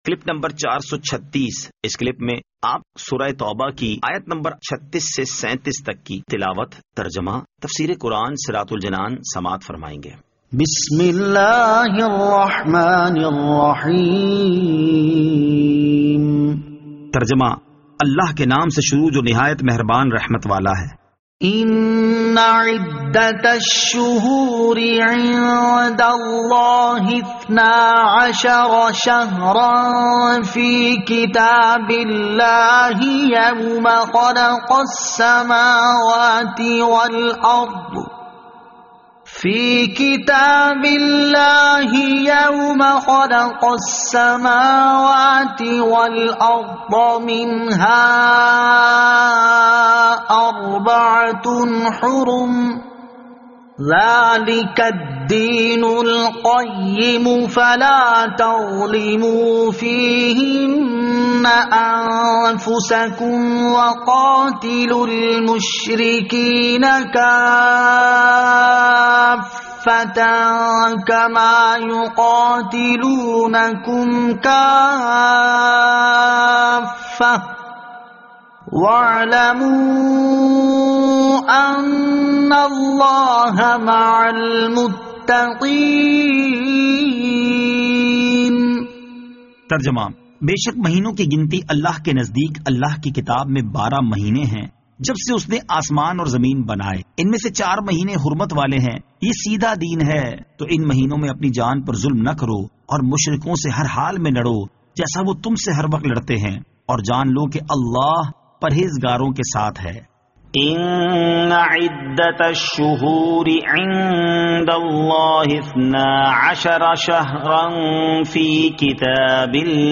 Surah At-Tawbah Ayat 36 To 37 Tilawat , Tarjama , Tafseer